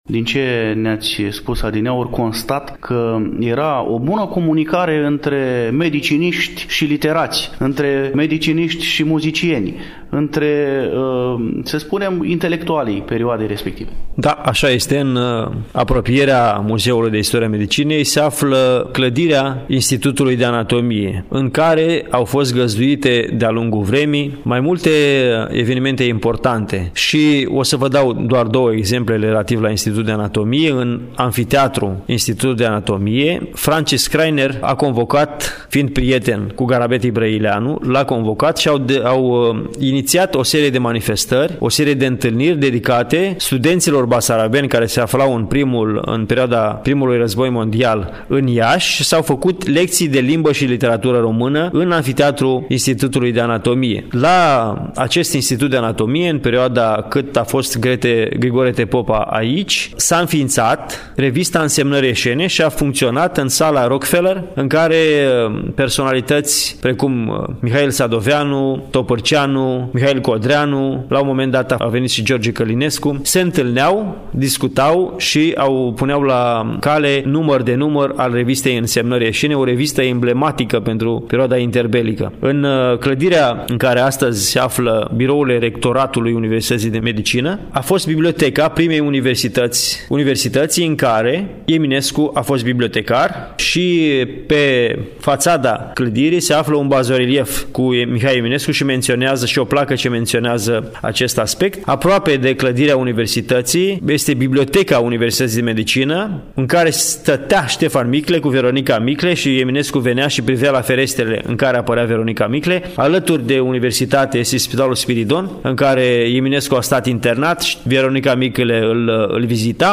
Realtăm din incinta Muzeului de Istorie a Medicinei din cadrul Universității de Medicină și Farmacie „Gr. T. Popa” Iași, Strada Universității, Numărul 16.